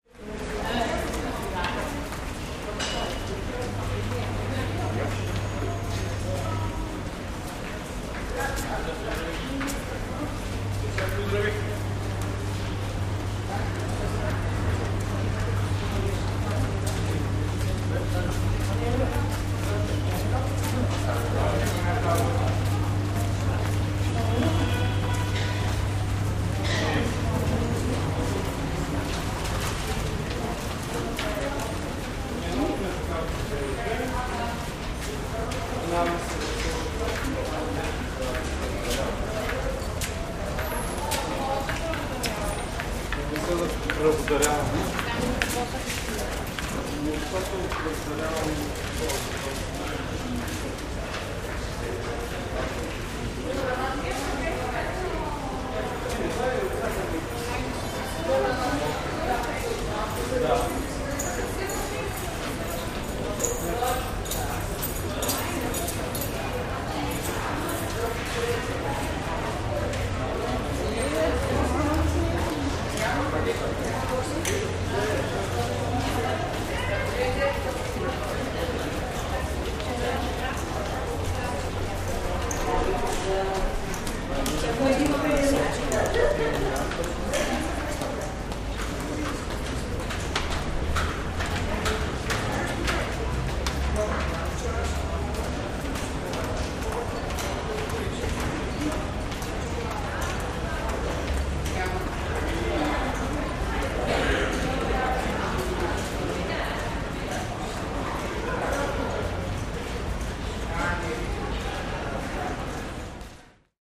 Pedestrians on Subway Ambience